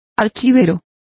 Complete with pronunciation of the translation of registrar.